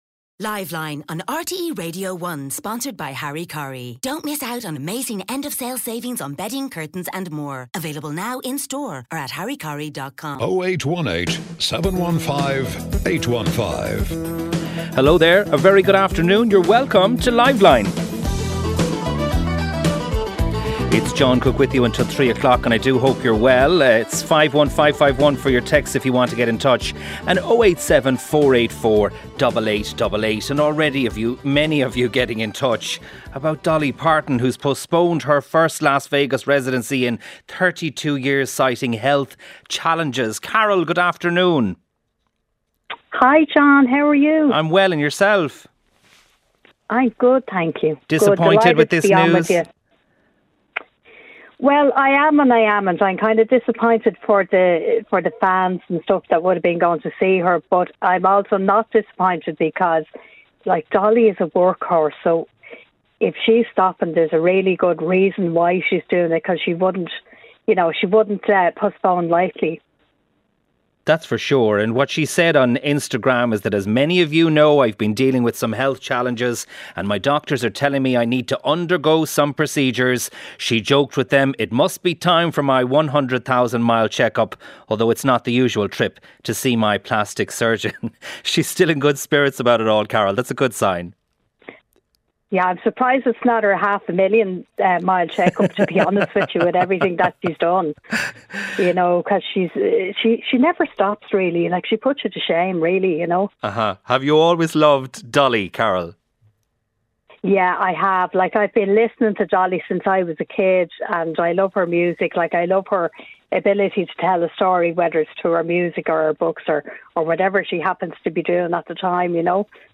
A number of listeners got in touch with the programme wondering what the Budget will mean for them. Listeners talk of their love for Dolly!